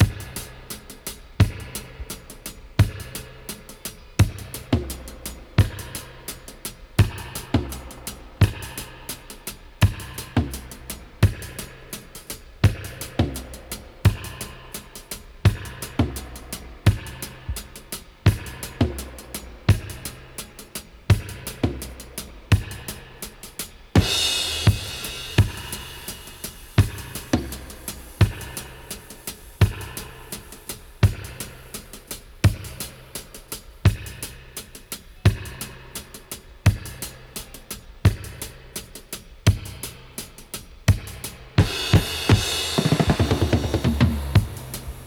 85-DUB-04.wav